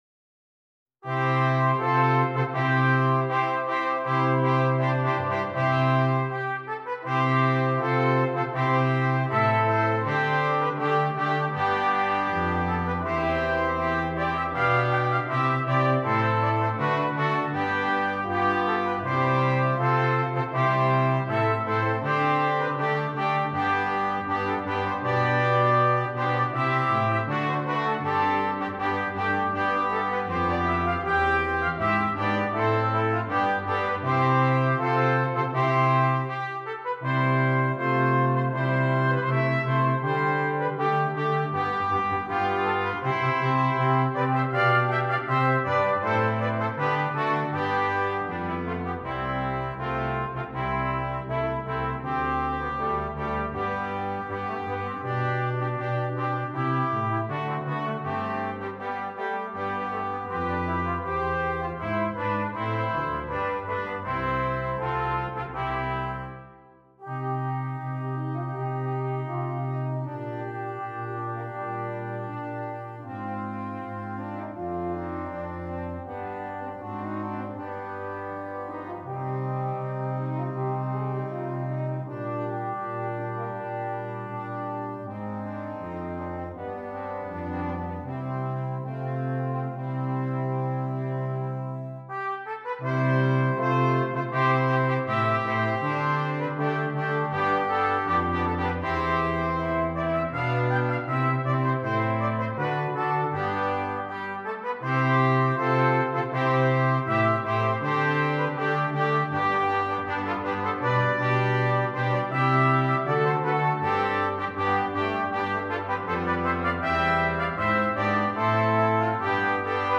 Brass Quintet
Norwegian Folk Song